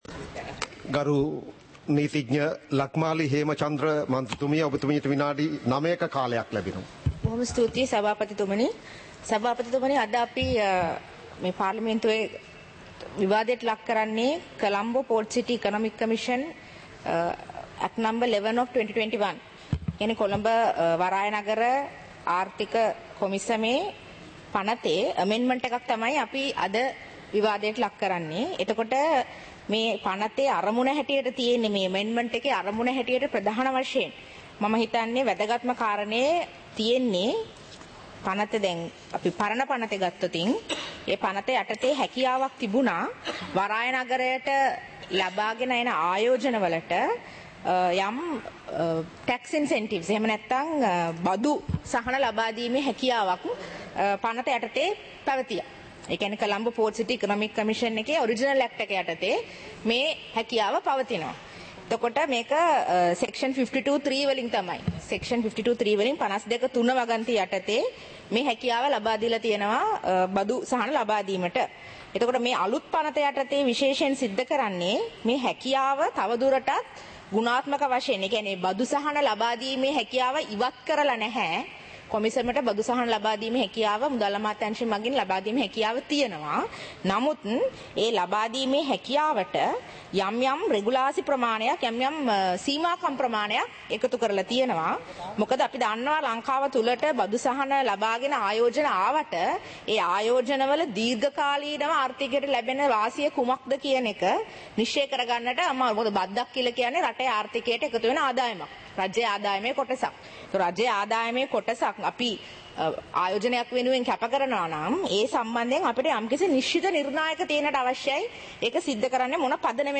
සභාවේ වැඩ කටයුතු (2026-01-07)